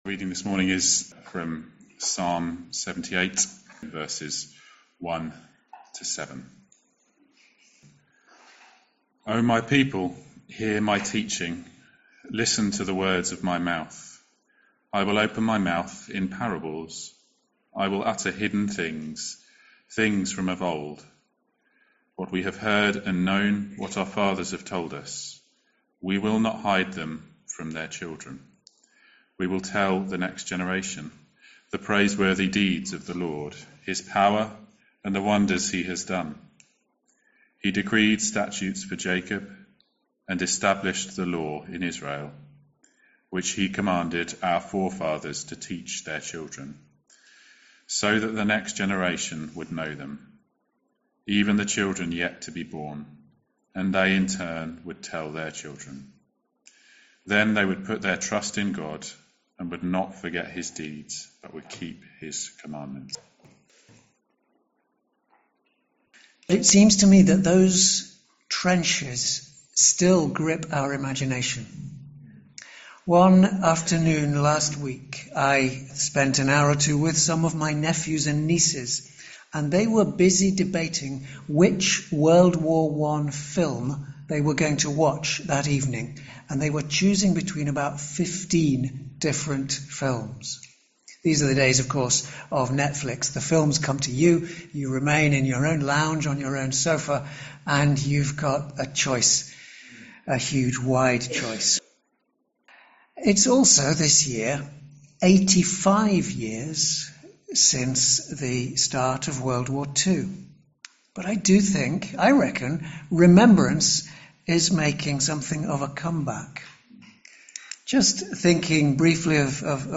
Sermons - Swanfield Chapel